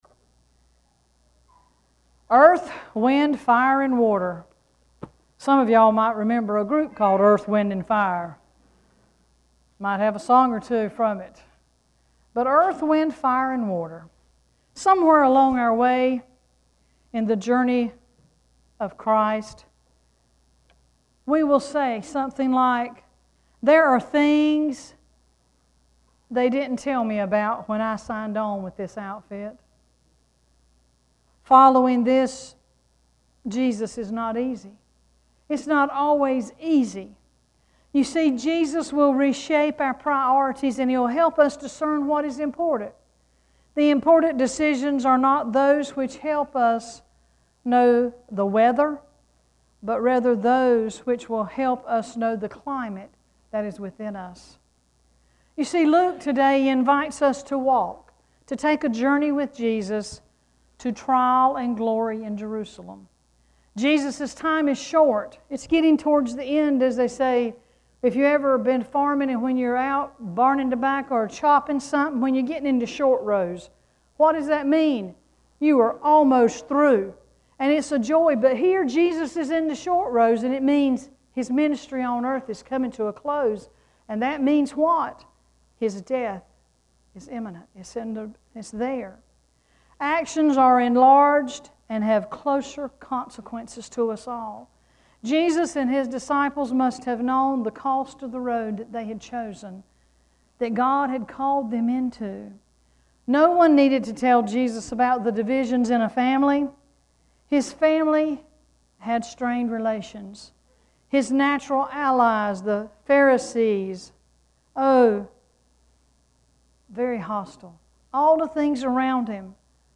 Sermon
8-14-sermon.mp3